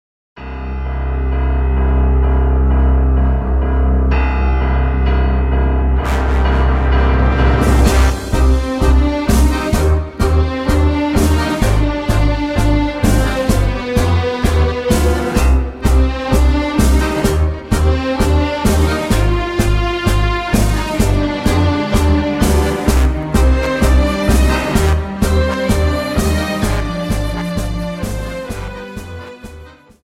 Dance: Tango